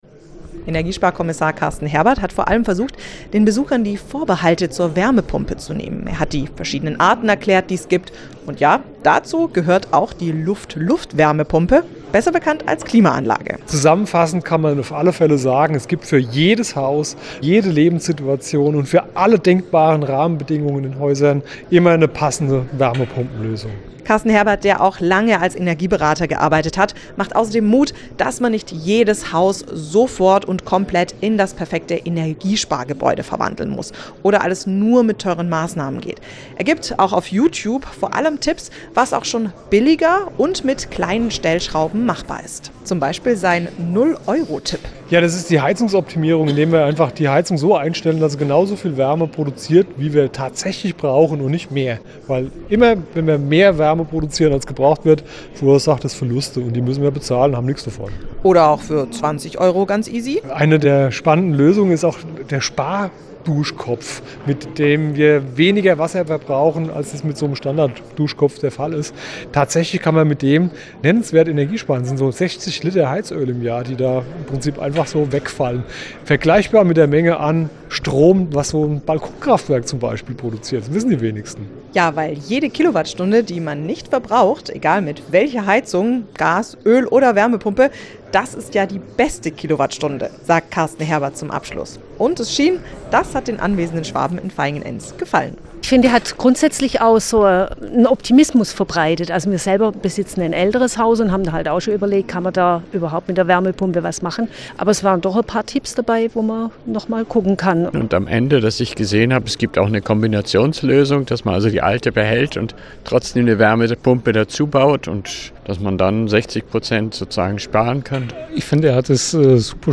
Interview mit Energieberater: "Der Umstieg auf Wärmepumpen wird nicht über Nacht passieren"